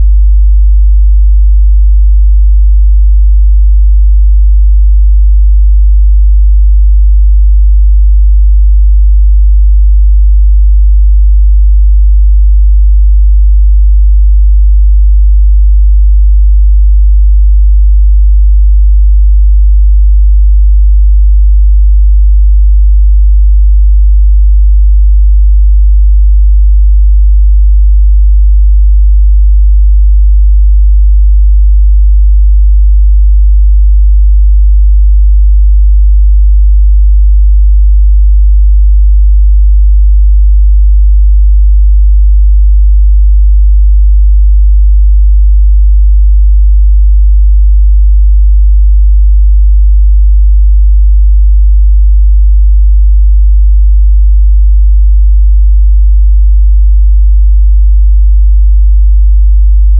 50Hz sinus.wav